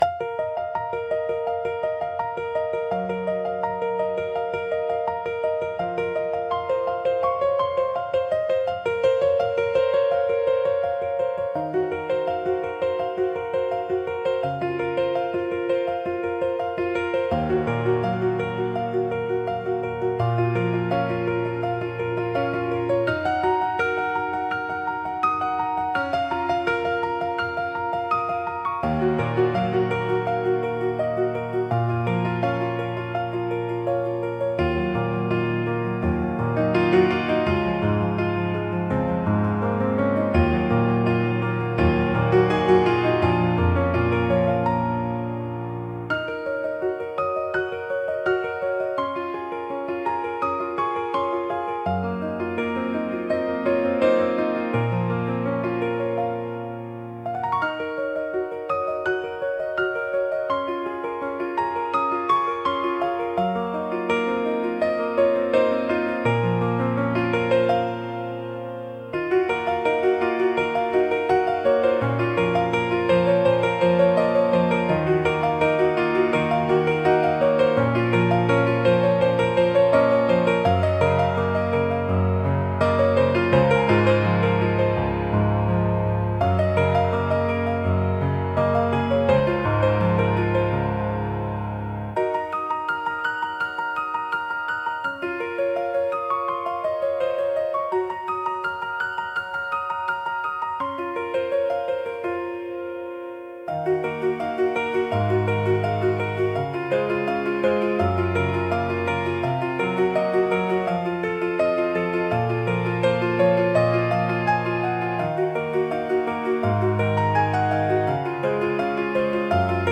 Classical Music “Classical Focus”